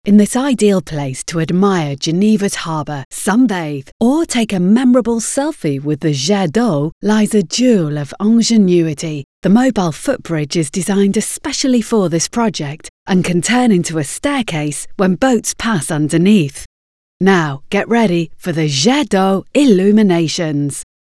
Anglais (Britannique)
Distinctive, Polyvalente, Amicale